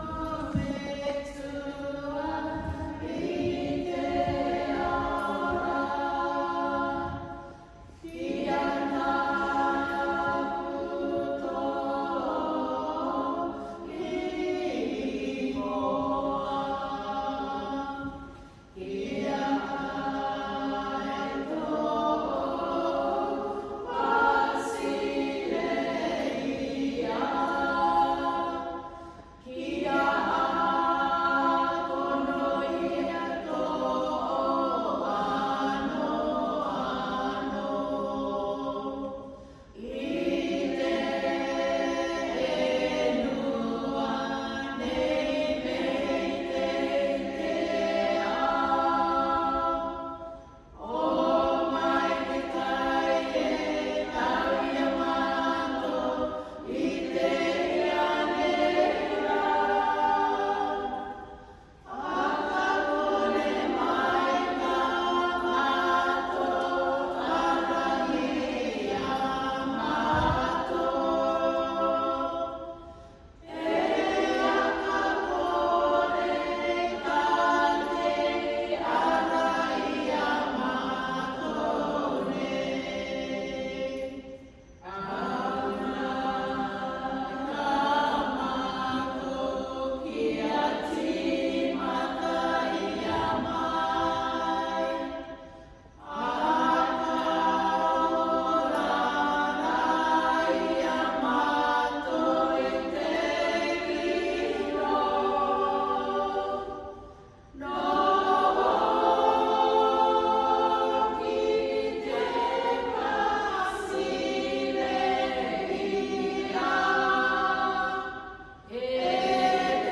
Musiques et chants pour la célébration :